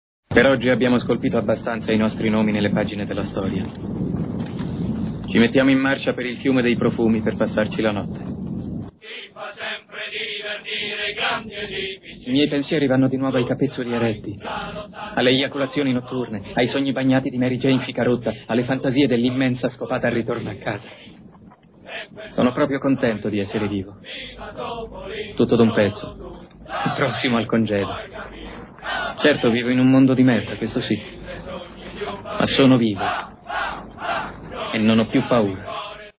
voce di Mattia Sbragia nel film "Full Metal Jacket", in cui doppia Matthew Modine.